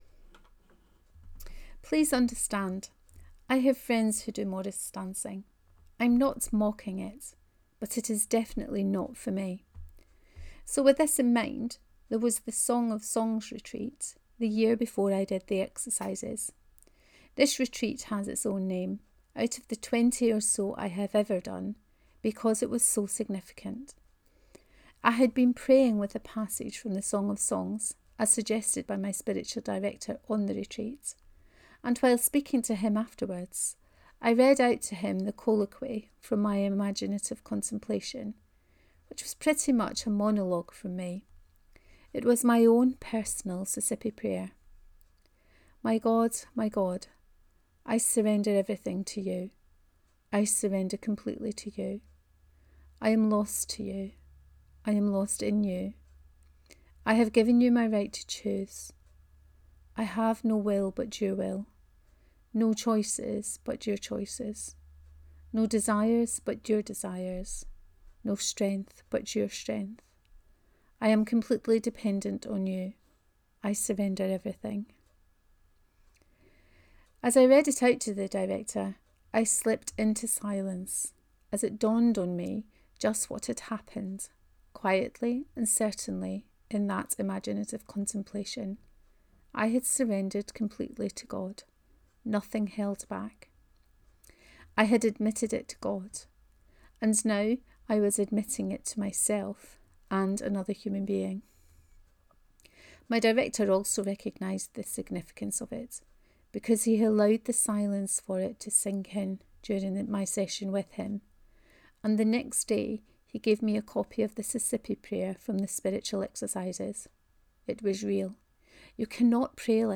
Morris dancing with the Holy Spirit 4: Reading of this post.